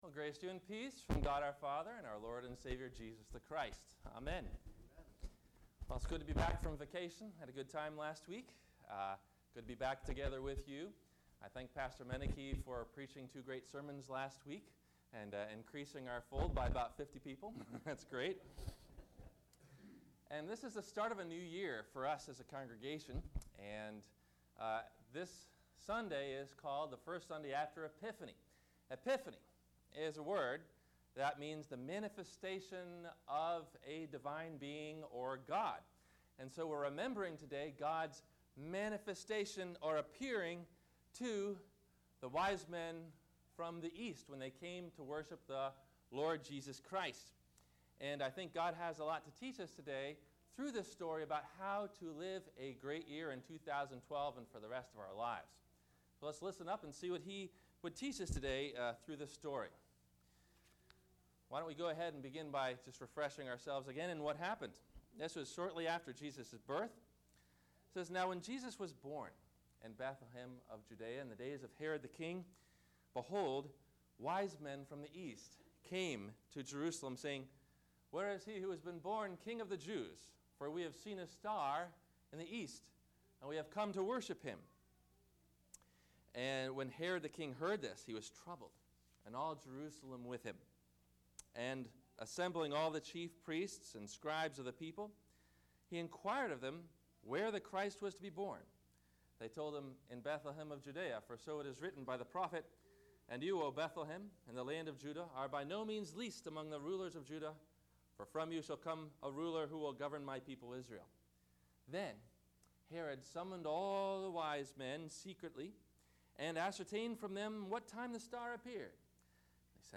A Hopeful Future - Sermon - January 08 2012 - Christ Lutheran Cape Canaveral